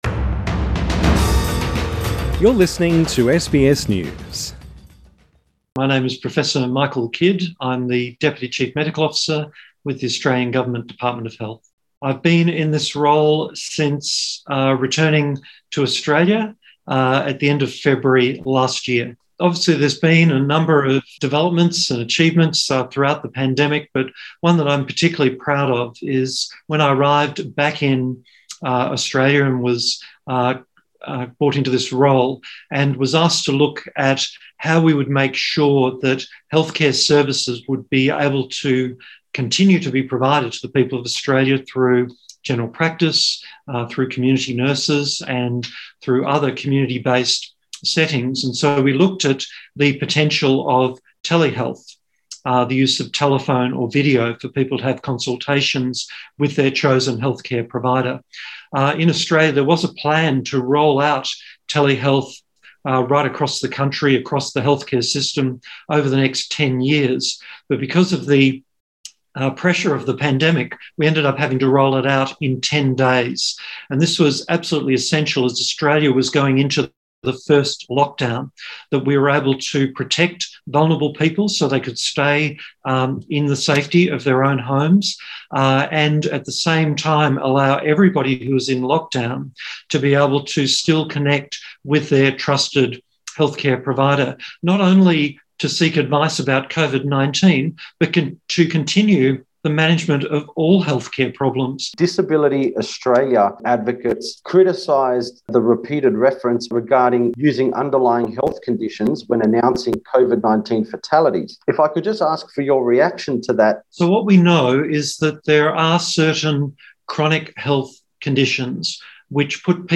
Q&A with Australia's Deputy Chief Medical Officer